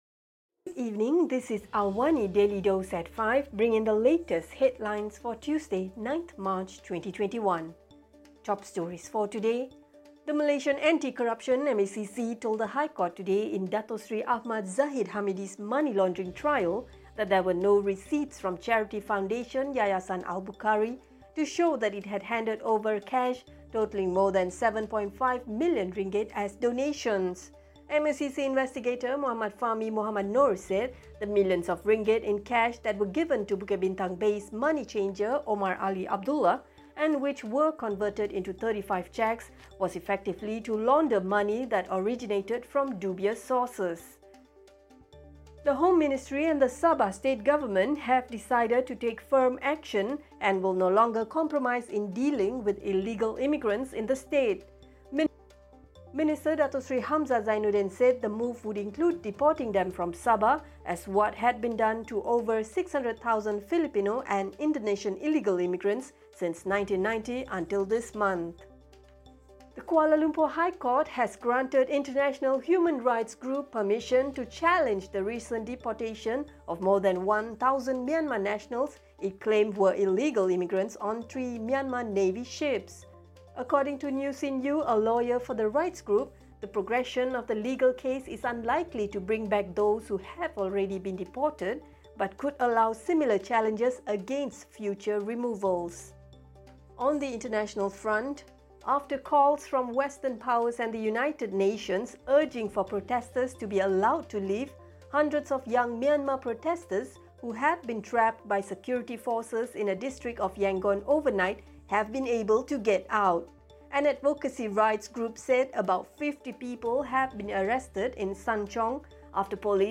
Also, hundreds of young Myanmar protesters who had been trapped by security forces in a district of Yangon overnight have been able to get out, after calls from western powers and the United Nations for them to be allowed to leave. Listen to the top stories of the day, reporting from Astro AWANI newsroom — all in 3-minutes.